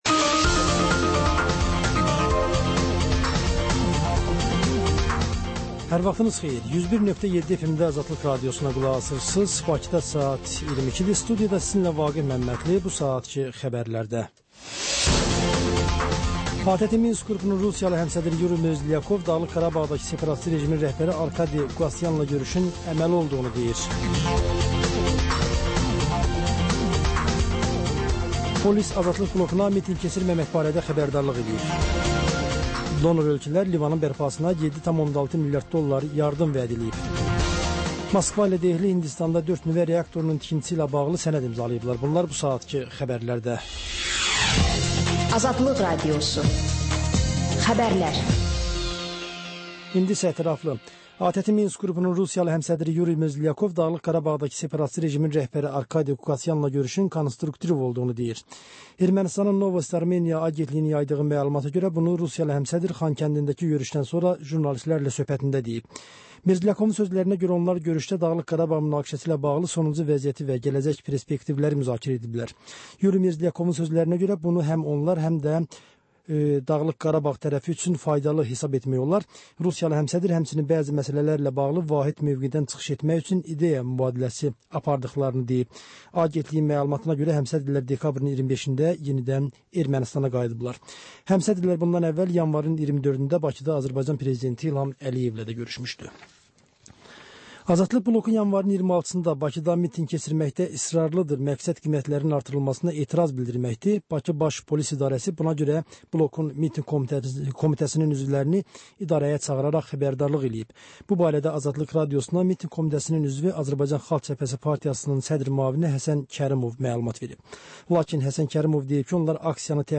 Xəbərlər
Xəbər, reportaj, müsahibə.